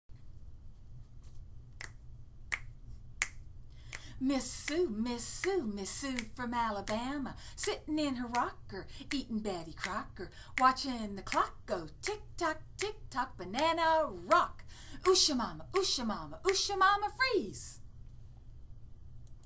(Snap your fingers as you repeat the chant)